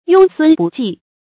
饔飧不济 yōng sūn bù jì 成语解释 同“饔飧不继”。